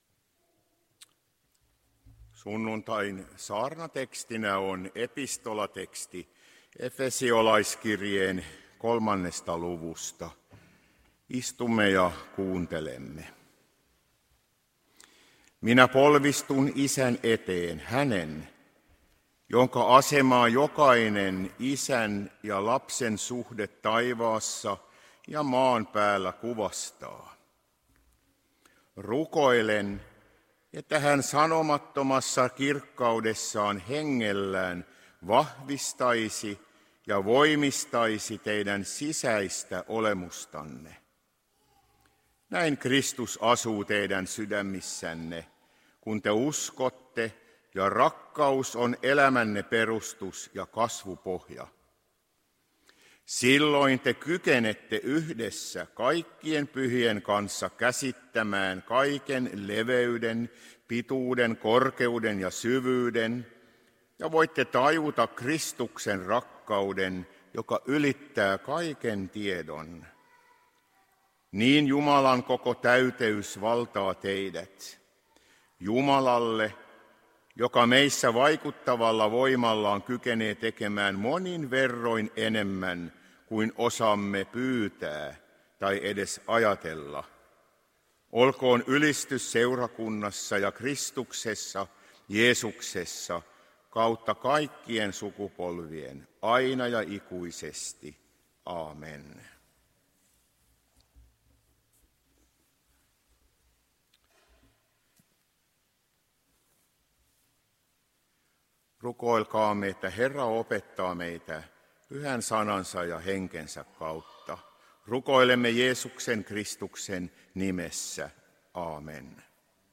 Saarnateksti: Ef. 3:14-21